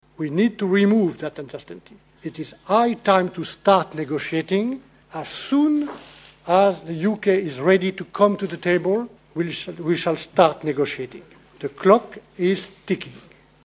03-mai-14-Michel-Barnier-netradus-ceasul-incepe-sa-bata-.mp3